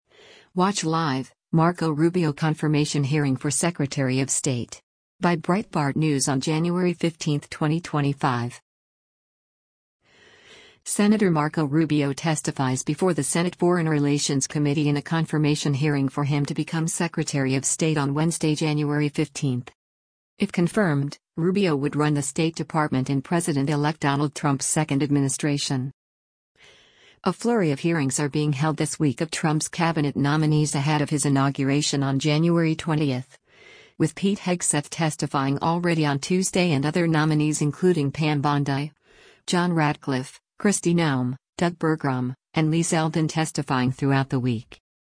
Sen. Marco Rubio testifies before the Senate Foreign Relations Committee in a confirmation hearing for him to become Secretary of State on Wednesday, January 15.